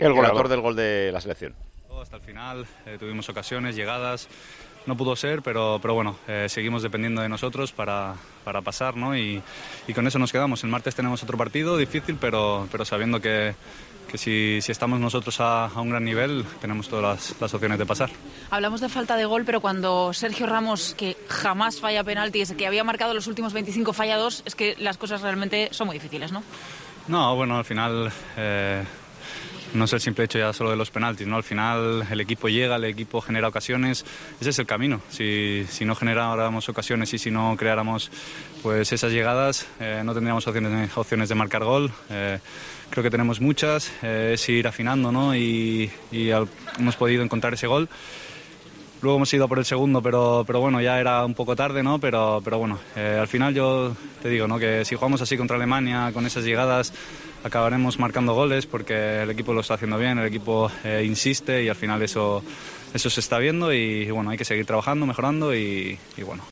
El goleador ante Suiza, en los micrófonos de RTVE: "No pudo ser, pero seguimos dependiendo de nosotros, nos quedamos con eso".